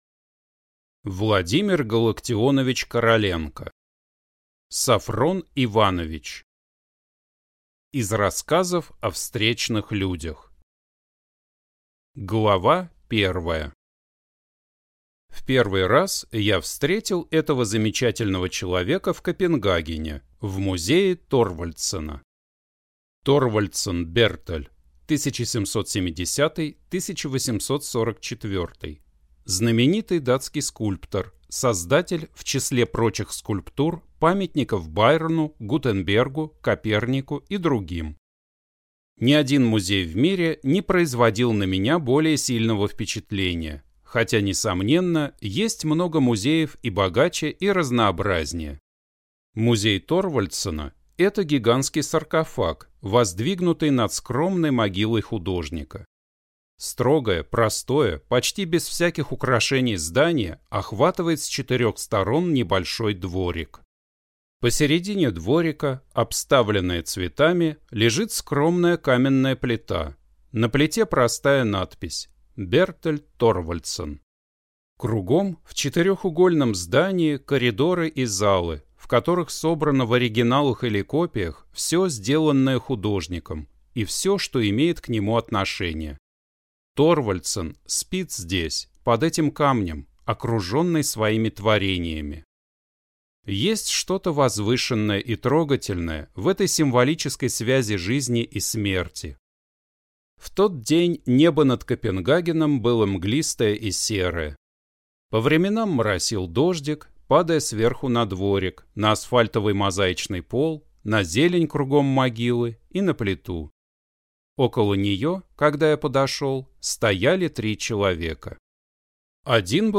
Аудиокнига Софрон Иванович | Библиотека аудиокниг